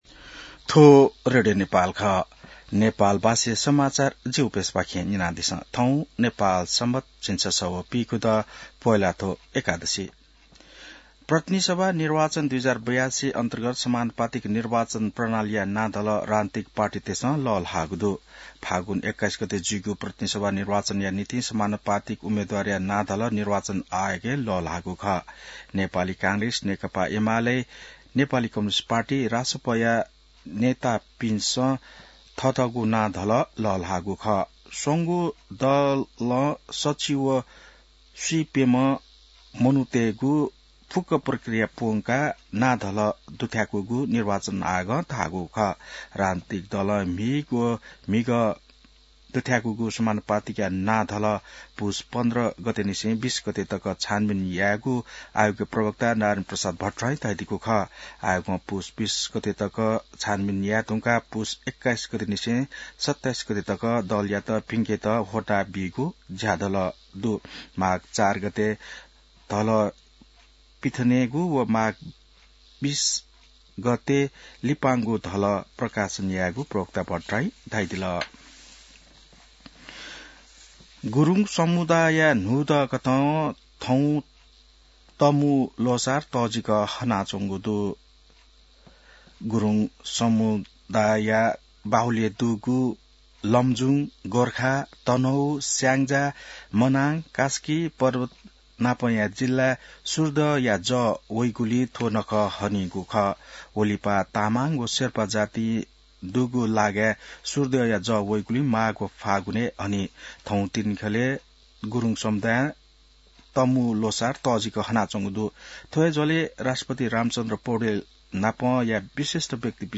नेपाल भाषामा समाचार : १५ पुष , २०८२